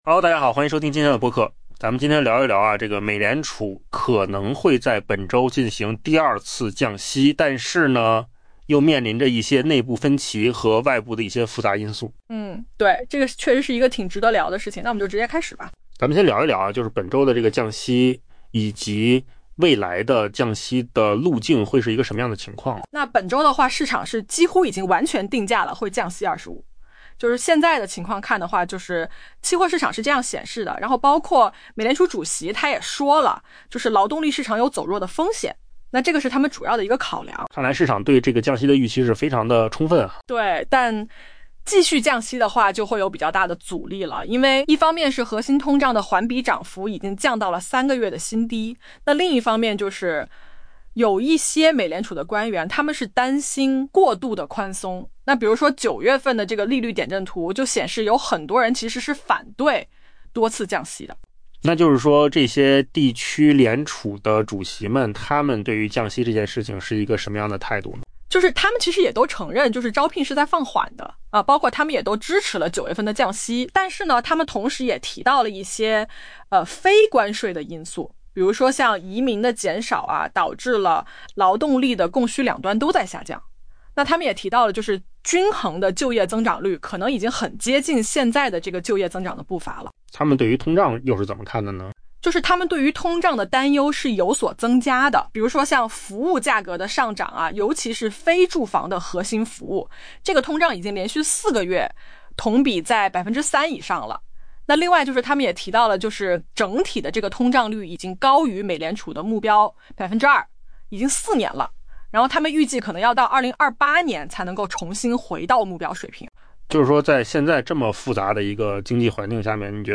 AI 播客：换个方式听新闻 下载 mp3 音频由扣子空间生成 美联储本周预计将实施连续第二次降息，以支撑持续疲软的就业市场。